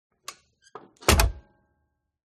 Звук закрытия люка танка